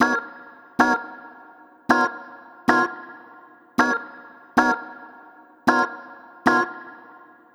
Swingerz 6 Organ-G#.wav